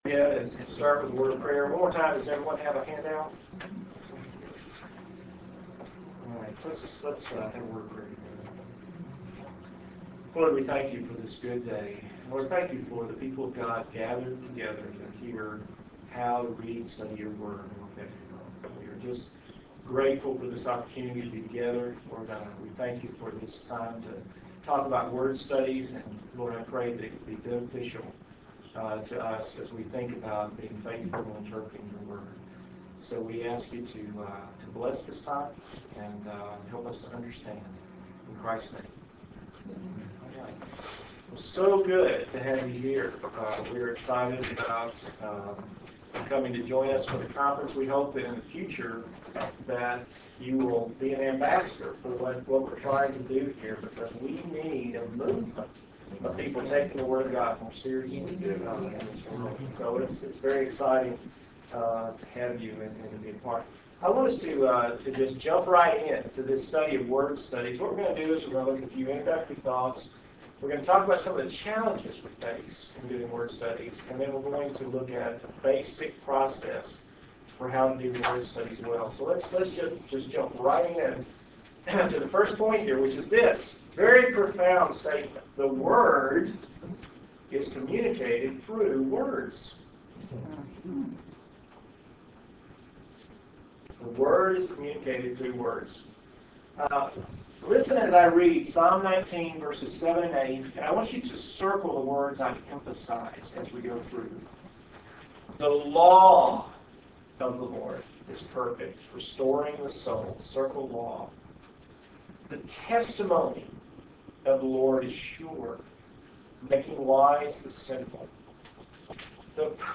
For All It's Worth Conference: Breakout Session